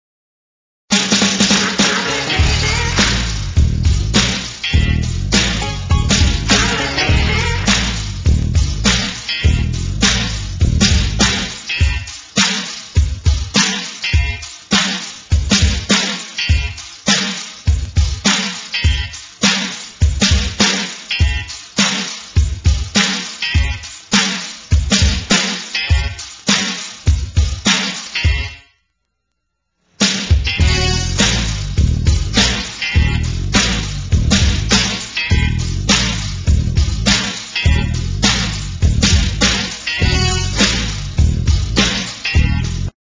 HIPHOP, RAP KARAOKE CDs